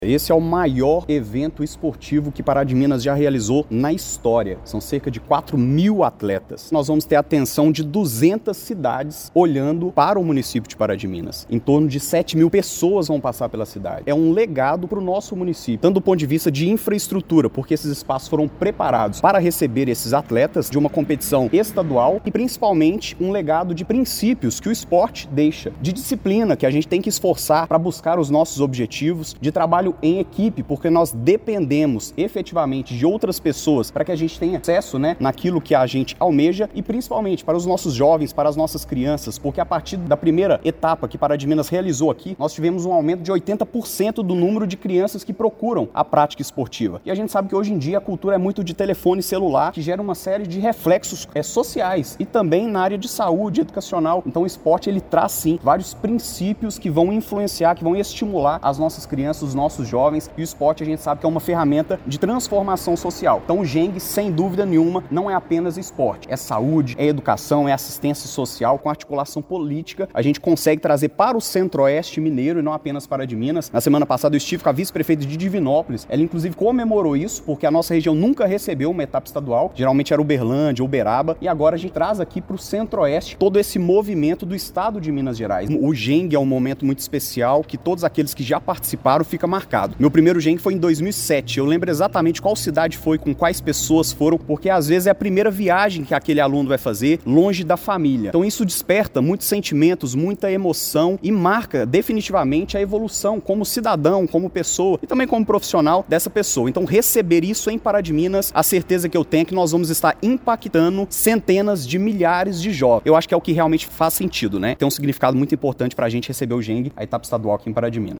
O vice-prefeito Luiz Fernando de Lima (Cidadania) complementa que o evento deixa um legado social e educacional para a cidade.